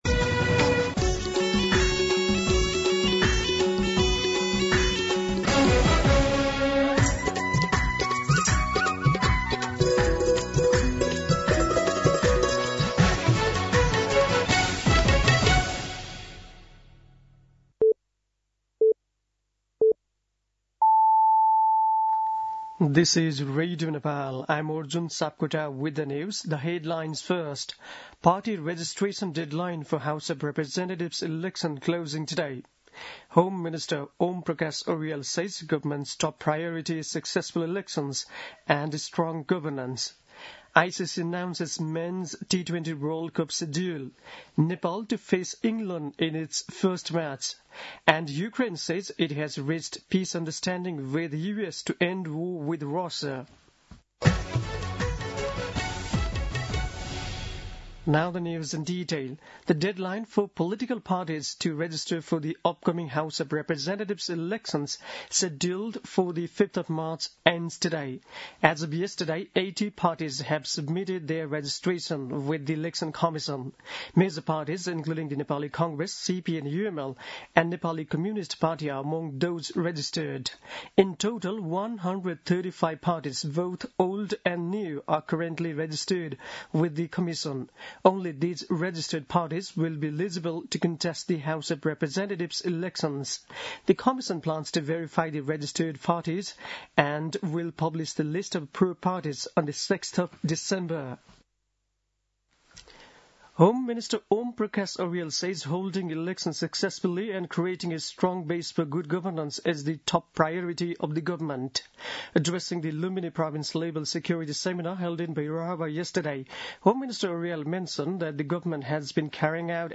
दिउँसो २ बजेको अङ्ग्रेजी समाचार : १० मंसिर , २०८२
2pm-English-News-10.mp3